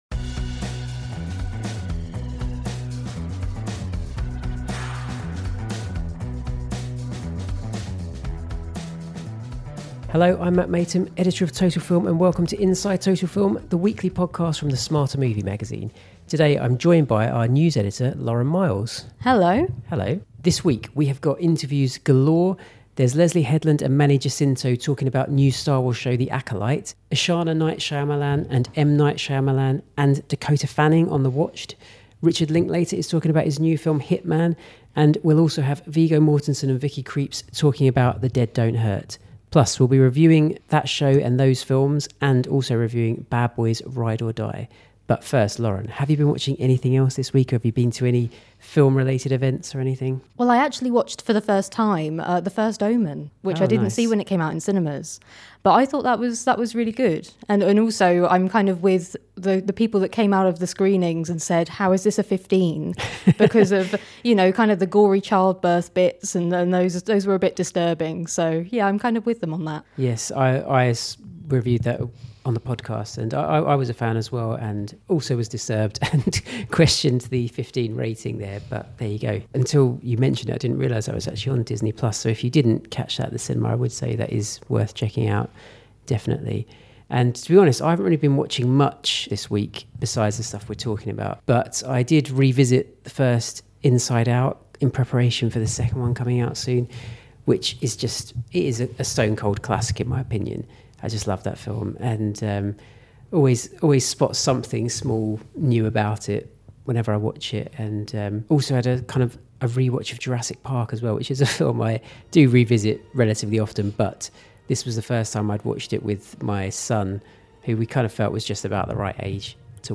Interview special: The Acolyte, The Watched, Hit Man, The Dead Don’t Hurt
Richard Linklater discusses his new film Hit Man, as it lands on Netflix. And Viggo Mortensen and Vicky Krieps tell us about their new western, The Dead Don’t Hurt. Plus, we’ll be reviewing all of those, as well as Bad Boy: Ride or Die.